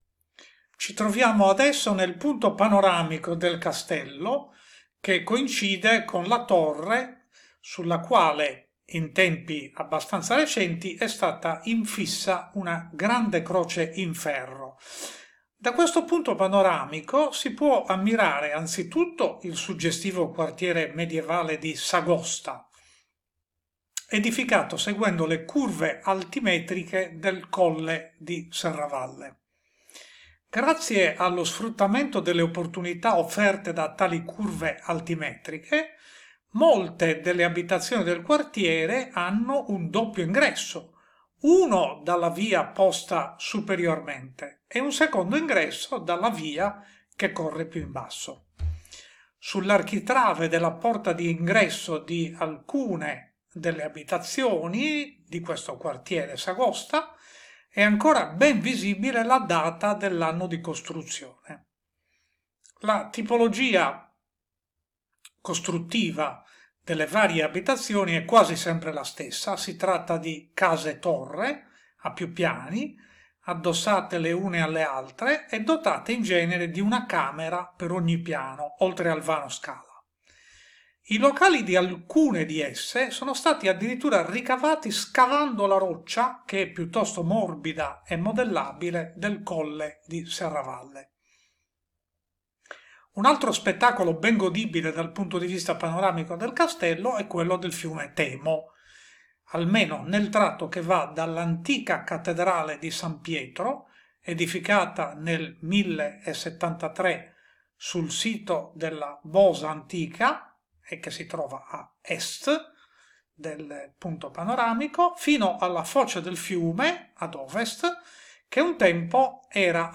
Audioguide - Audioguides